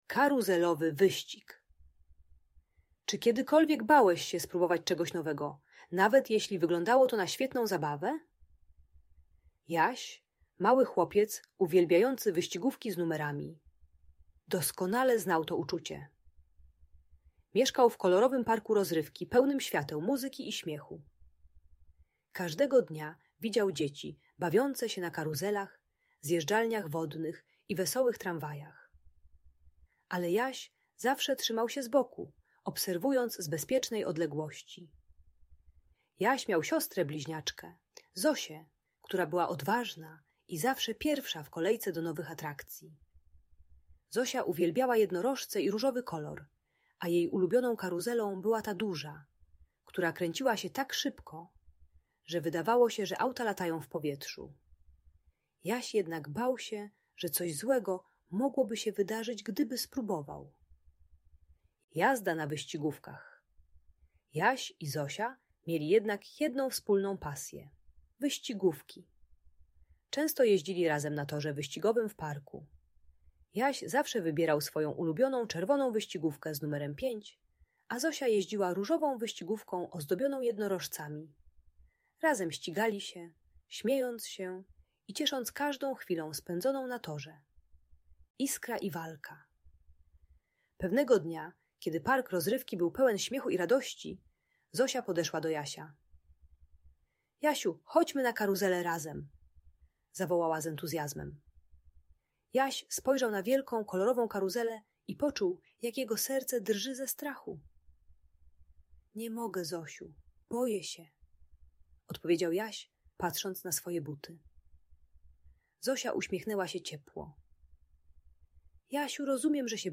Bajka dla dziecka które boi się próbować nowych rzeczy, idealna dla przedszkolaków 4-6 lat. Ta audiobajka o lęku i wycofaniu uczy techniki małych kroków z wsparciem bliskiej osoby. Pomaga dziecku zrozumieć, że strach przed nowością jest naturalny, ale można go pokonać z pomocą rodziny czy przyjaciela.